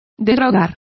Complete with pronunciation of the translation of revoke.